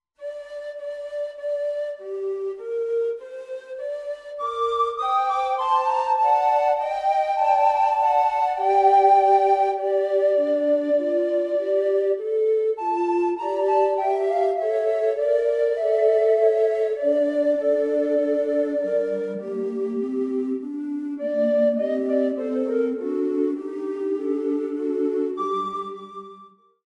Music composed during the 15th, 16th and 17th centuries.